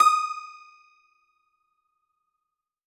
53q-pno19-D4.aif